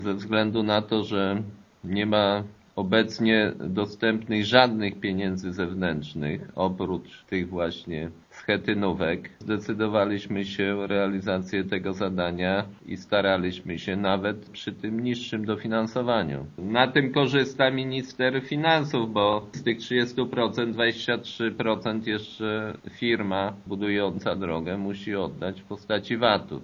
Wójt Jacek Aanasiewicz przyznaje, że to zdecydowanie gorsze warunki, ale gmina zdecydowała się z nich skorzystać, ponieważ... nie ma innego wyjścia: